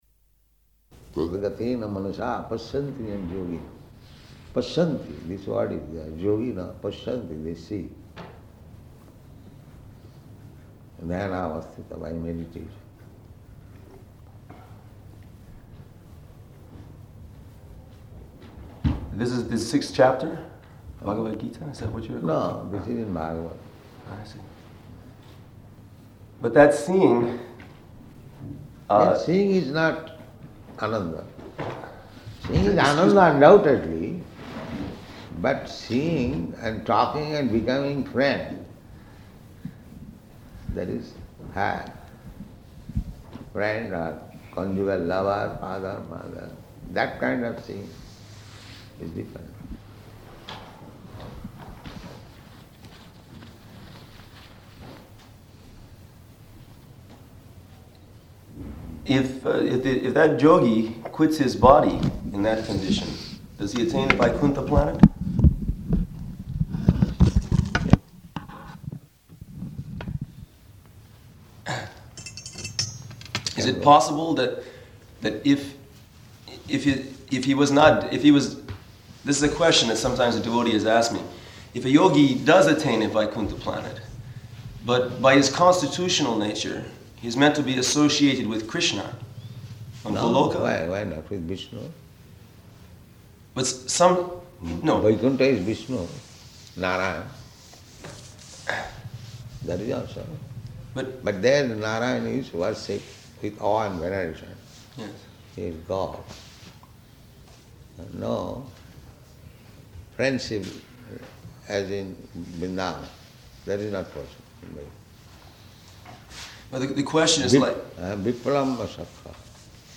Room Conversation
Location: London